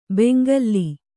♪ bengalli